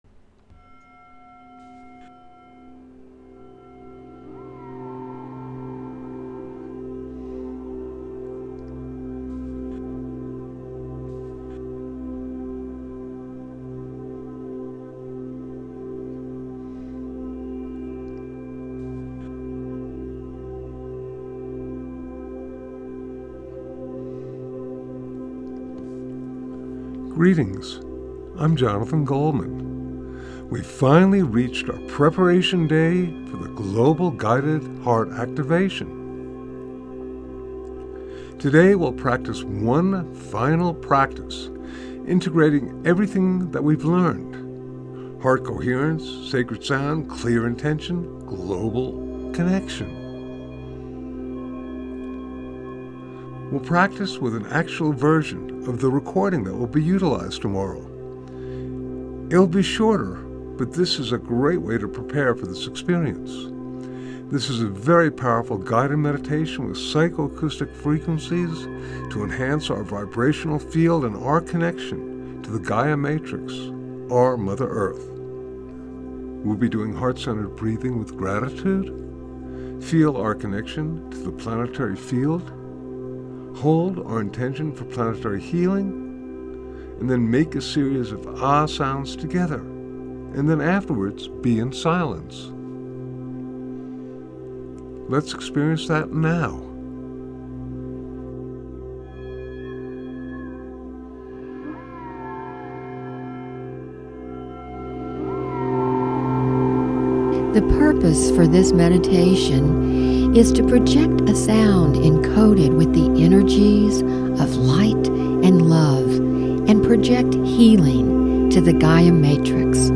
It’s a shorter edit of the piece, but still a great way to prepare for the experience. This is a very powerful guided meditation featuring voice over
a group chanted AH tone enhanced by psycho-acoustic frequencies to amplify our vibrational field and our connection to the Gaia Matrix—our Mother Earth.